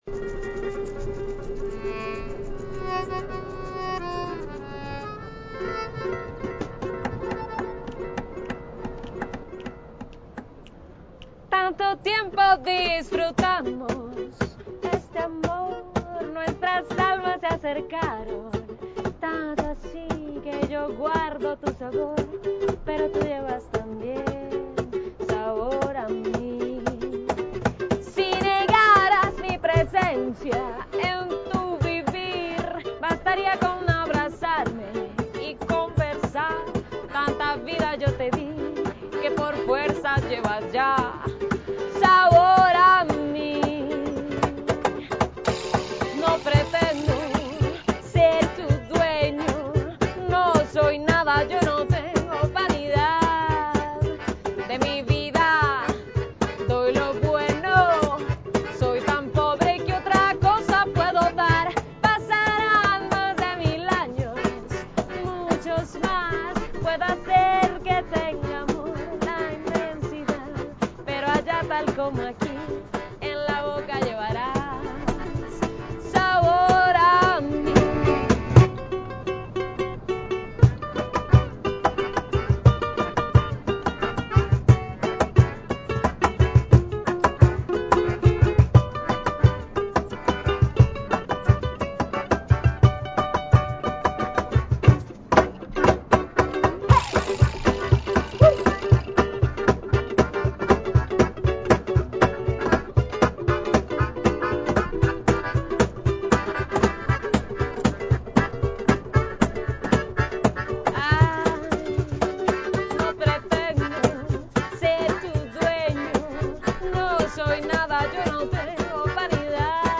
Compartiendo de nuevo una entrevista de la clase de Radio y Noticias